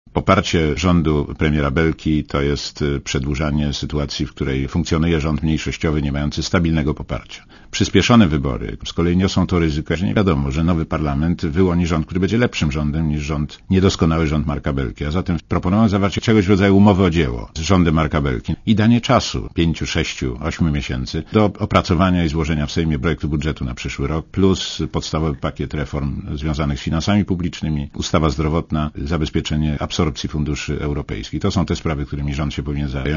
„Proponujemy zawarcie »umowy o dzieło« z rządem Marka Belki i danie czasu – pięciu, sześciu, ośmiu miesięcy – do opracowania i złożenia w Sejmie projektu budżetu na przyszły rok oraz podstawowego pakietu reform, związanego z finansami publicznymi, ustawą zdrowotną, zabezpieczeniem absorpcji funduszy Unii Europejskiej” – powiedział Rosati w Radiu ZET.